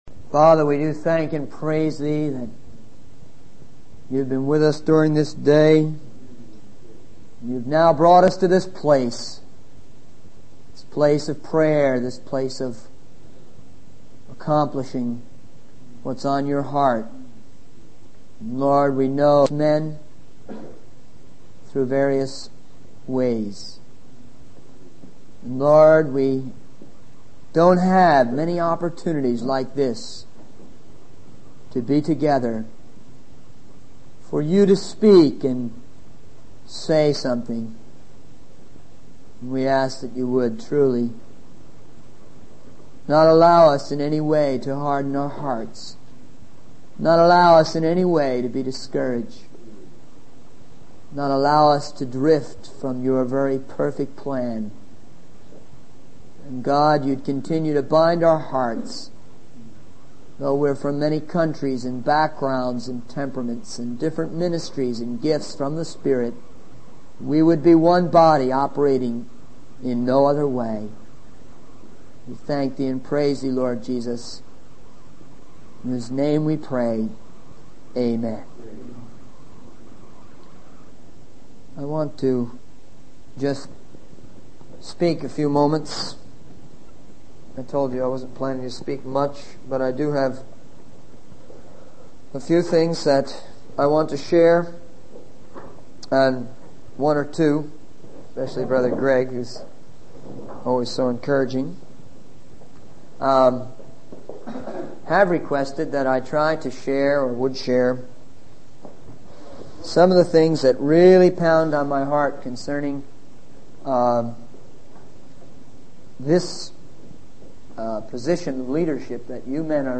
In this sermon, the speaker shares about the impact of distributing literature and gospel tracks in various cities.